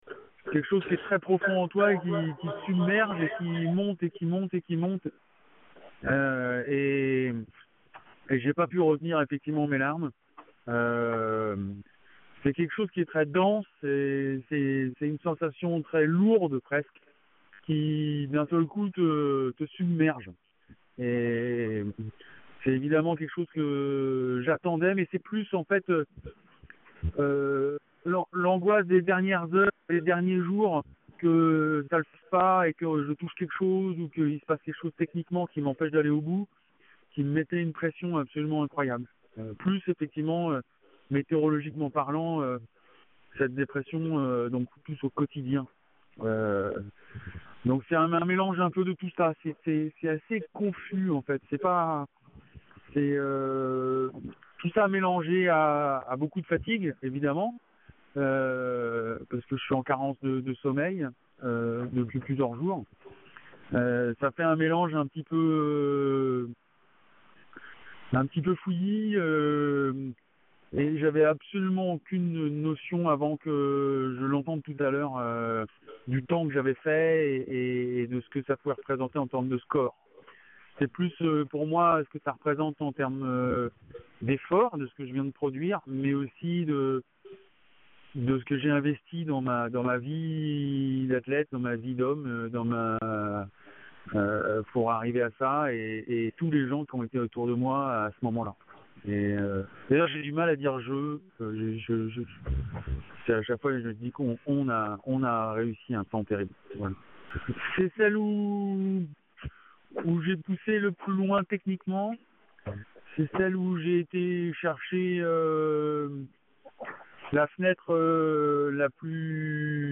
Le skipper du trimaran Sodebo Ultim’ a franchi la ligne du tour du monde en solitaire et en multicoque hier soir en moins de 50 jours. Voici ses premières déclarations à son arrivée ce matin au ponton à Brest :
Premières_impressions_de_Thomas_Coville_après_avoir_franchi_la_ligne.mp3